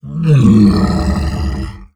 MONSTER_Growl_Medium_19_mono.wav